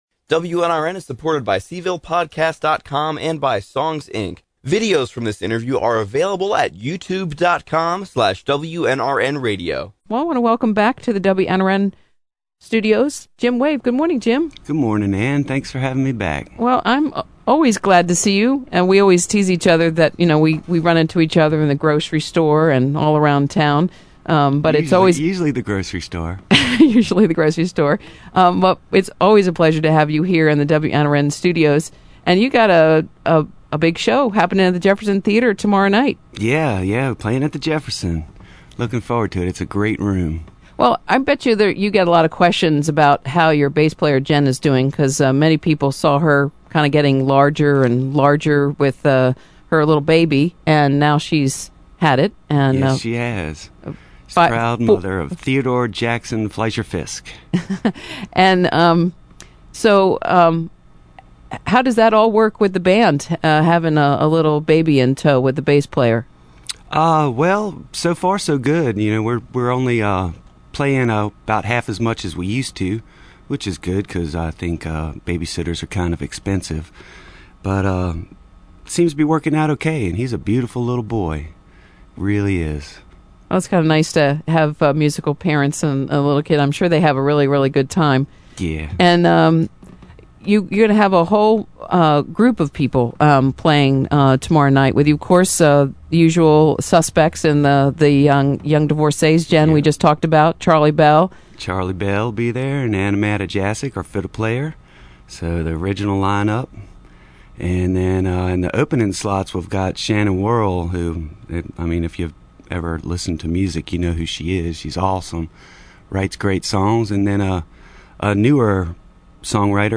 live songs
an interview